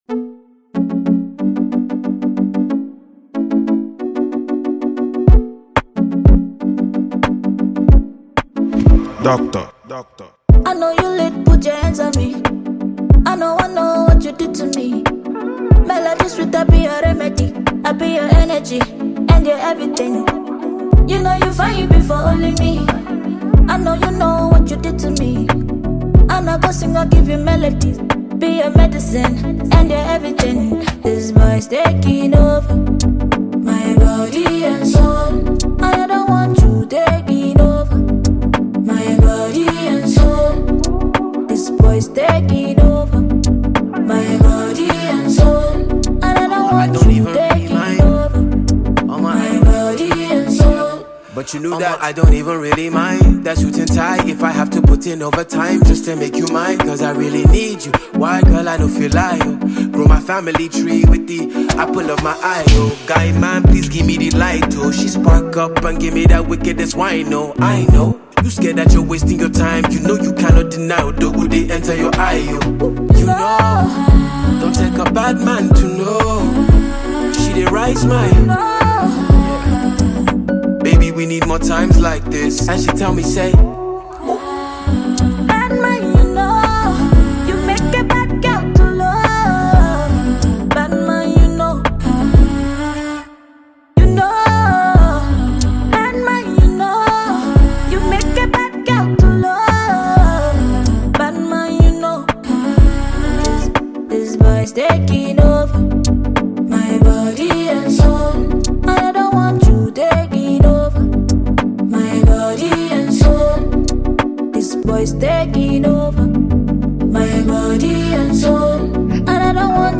On this melodious viral accepted afrobeats anthem